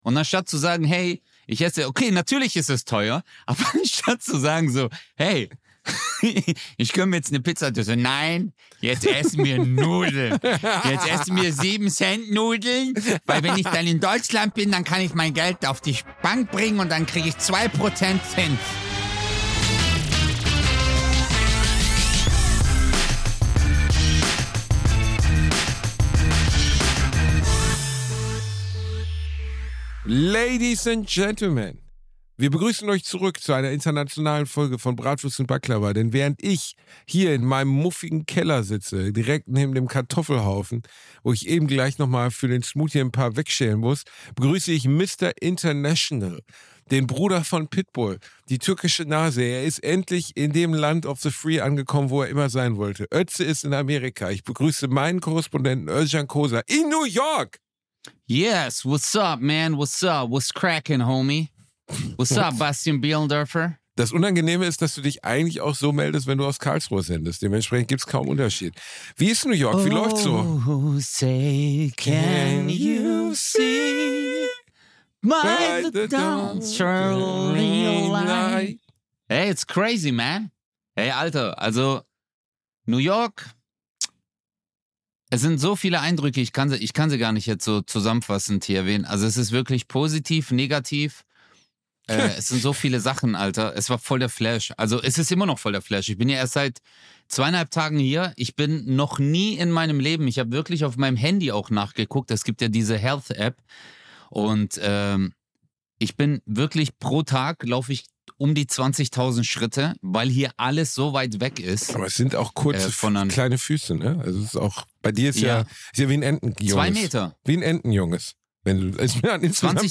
Trotz Zeitverschiebung und Ozean, der sie trennt nehmen die Jungs eine neue Folge zwischen Köln und New York auf. Sie sprechen über Özcans Eindrücke aus Amerika und Bastis Nudelspartipps. Es ist eine Folge zwischen Apple-Store Besuch und Walhai-Schwimmen.